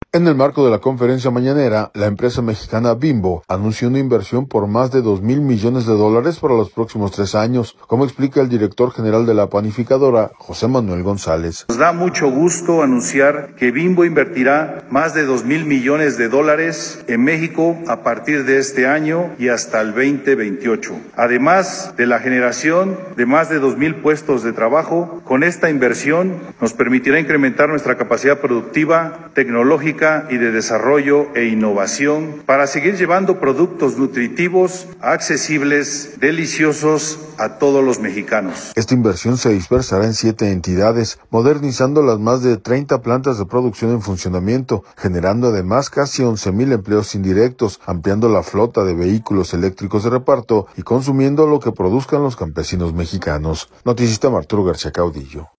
En el marco de la Conferencia Mañanera, la empresa mexicana Bimbo, anunció una inversión por más de dos mil millones de dólares para los próximos tres años